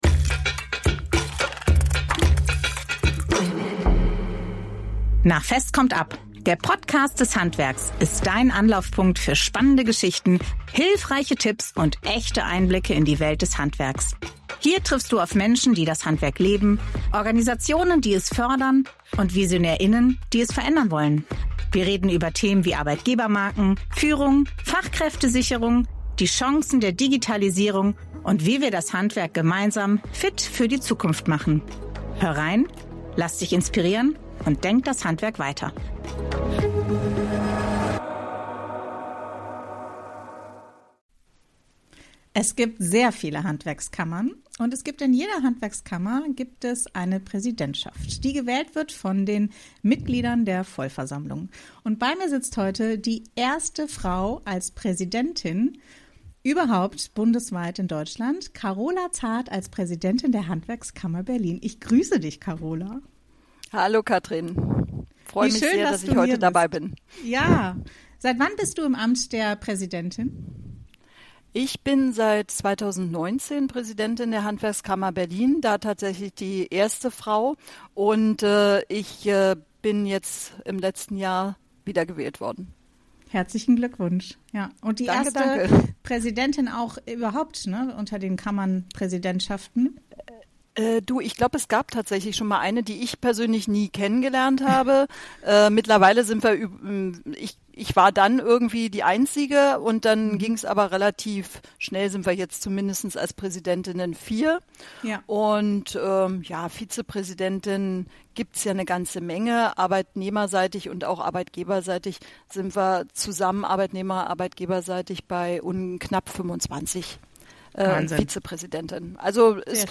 Ein Gespräch über 30 Jahre Engagement, über Nachwuchs im Ehrenamt und die Zukunft der Selbstverwaltung im Handwerk....